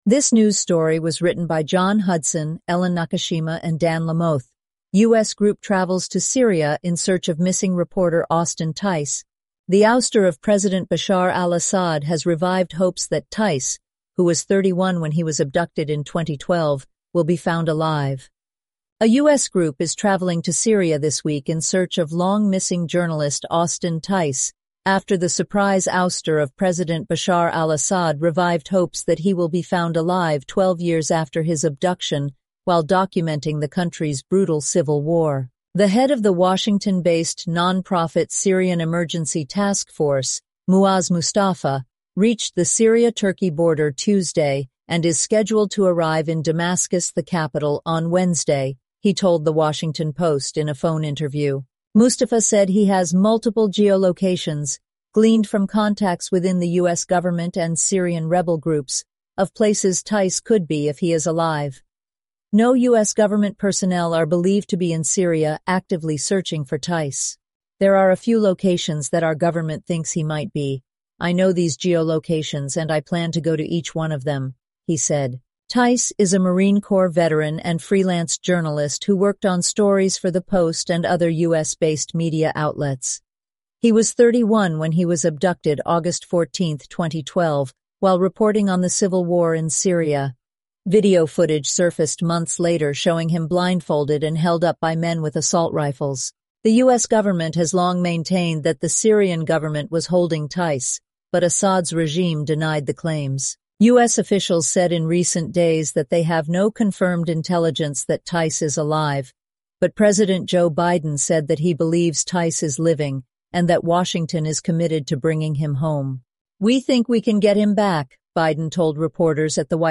eleven-labs_en-US_Maya_standard_audio.mp3